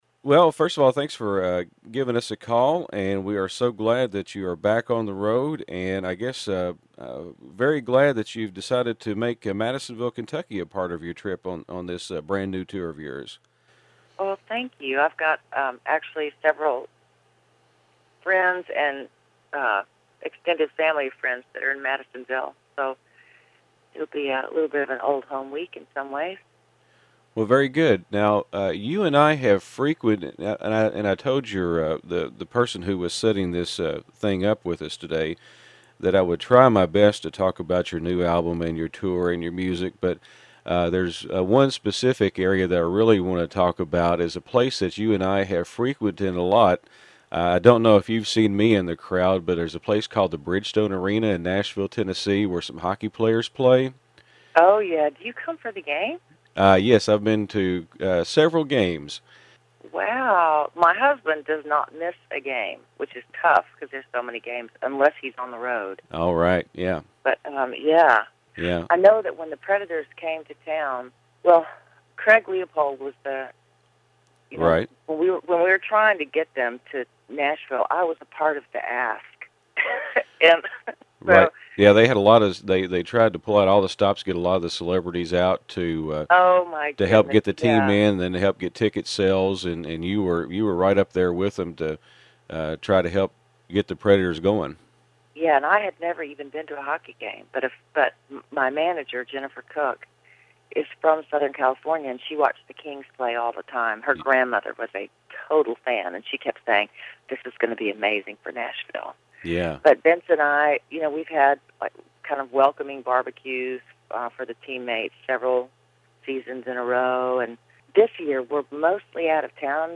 Interview with Amy Grant – 041610